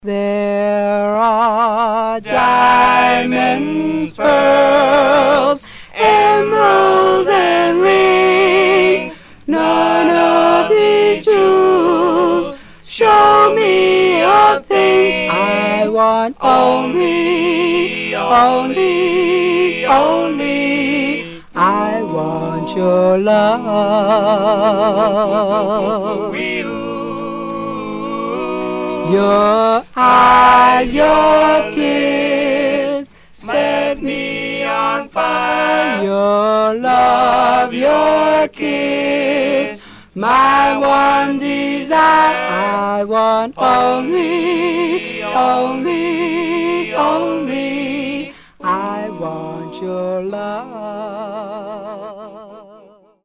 Barely played, Some surface noise/wear Stereo/mono Mono